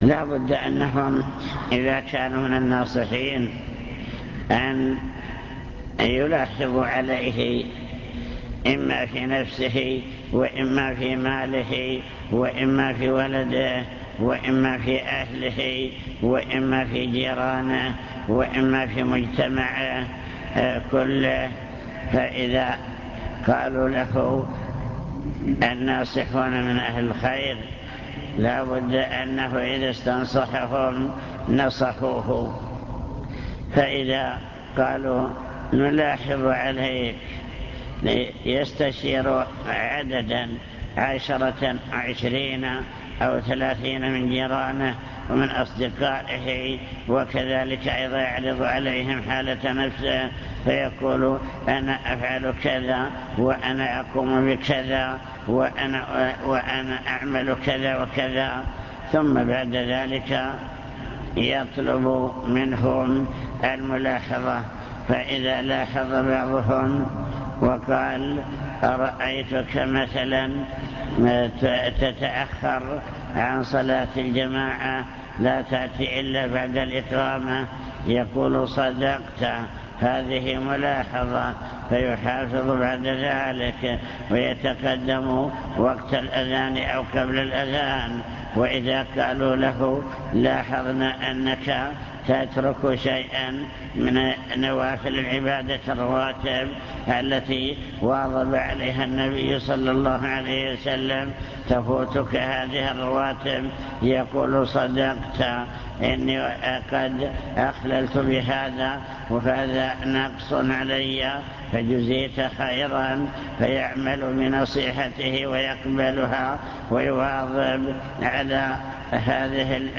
المكتبة الصوتية  تسجيلات - لقاءات  عوامل صلاح المجتمع (لقاء مفتوح)